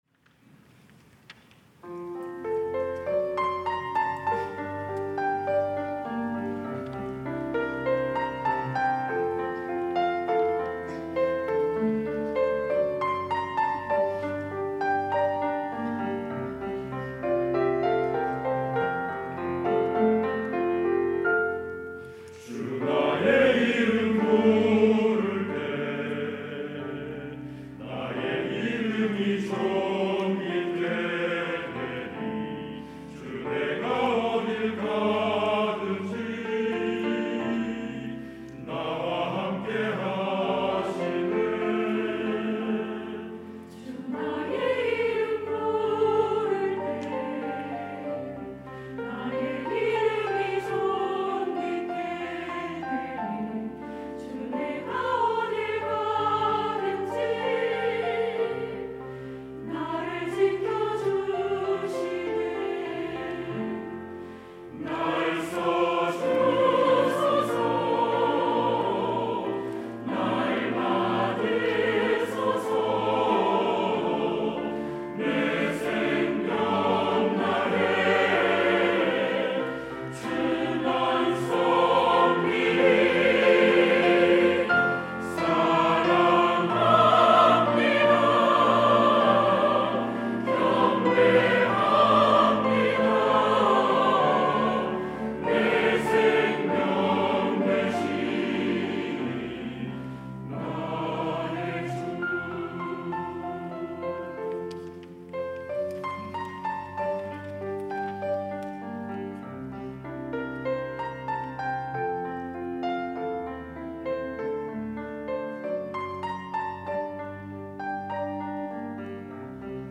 시온(주일1부) - 주 나의 이름 부를 때
찬양대